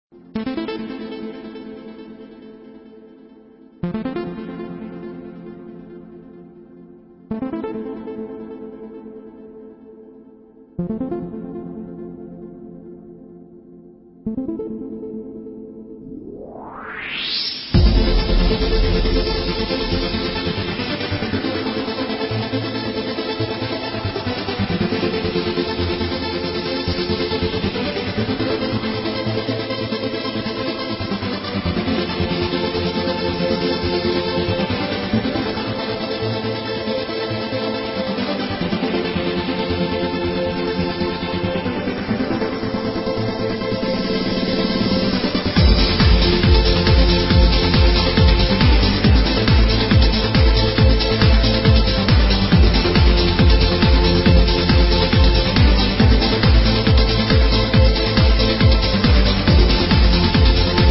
I need a title of this amazing trance track!